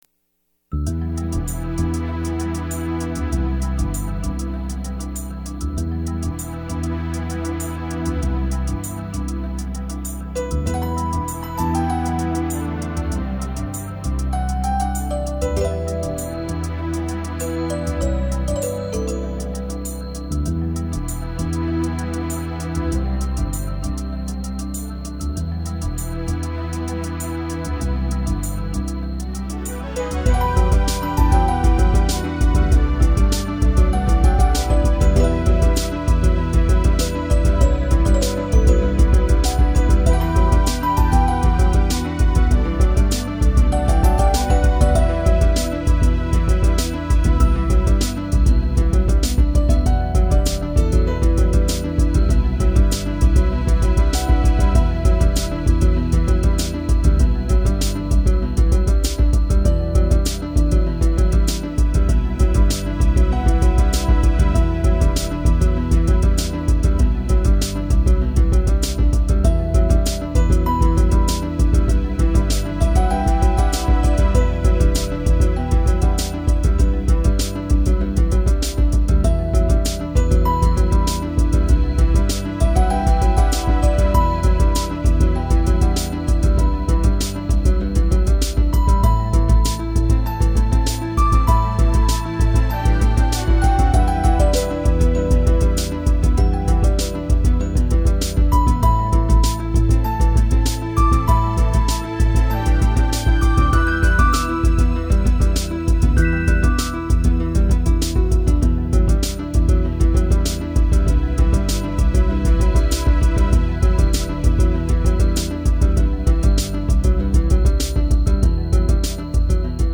雨が降り、風が吹き、そして雷が轟く・・・。